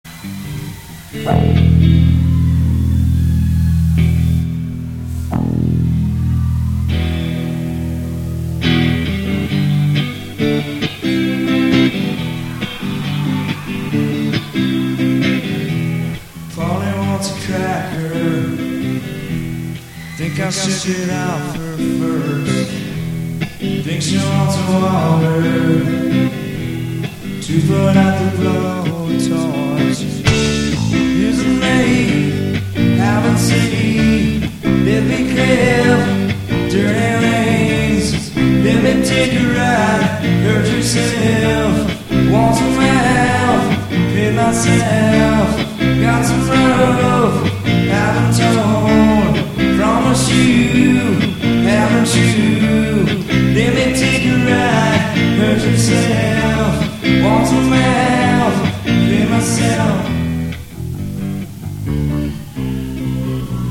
The Palace (Rock For Choice Benefit), Hollywood, CA, US